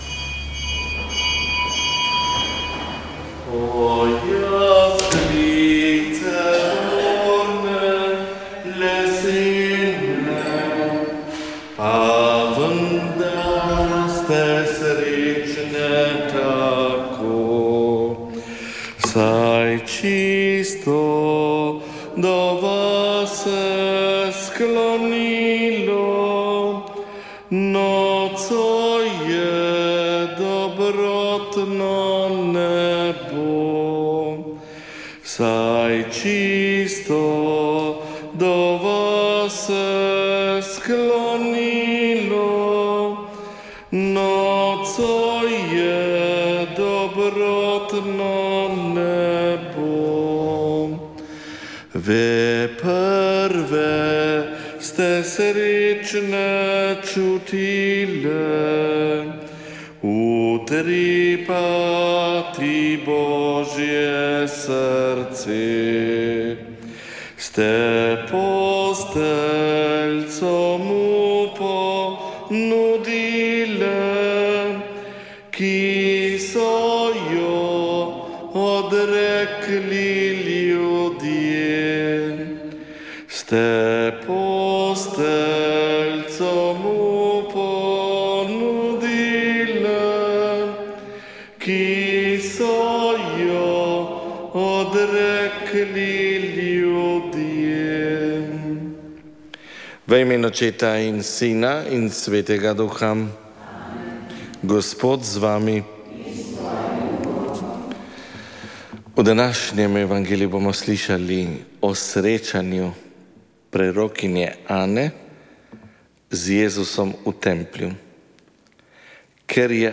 Sv. maša iz cerkve Marijinega oznanjenja na Tromostovju v Ljubljani 18. 12.
pel pa je zbor MPP